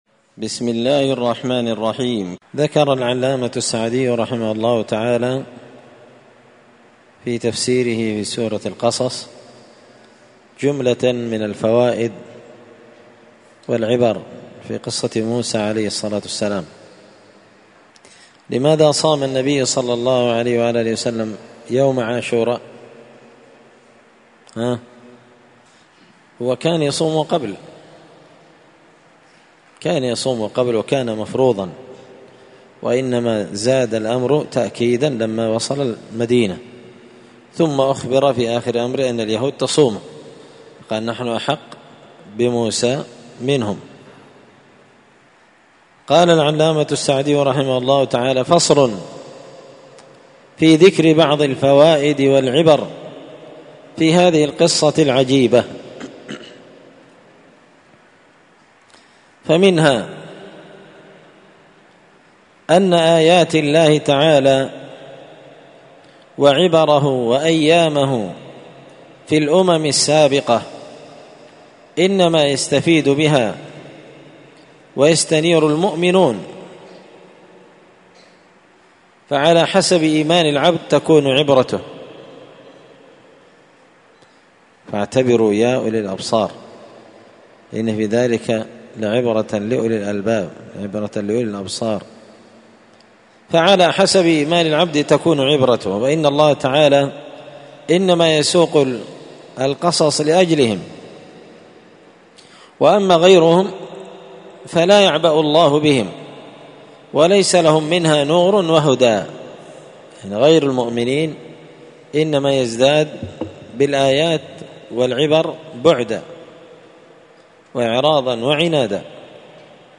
السبت 11 محرم 1445 هــــ | الدروس | شارك بتعليقك | 18 المشاهدات